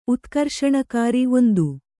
♪ utkarṣakāri